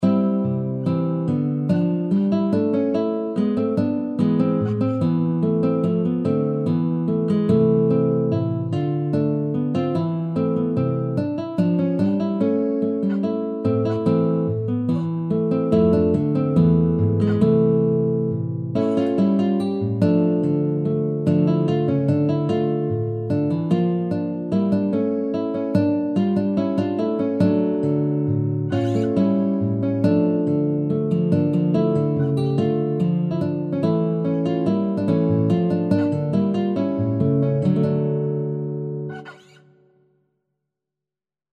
Adagio [70-80] tristesse - harpe - feodal - cour - rois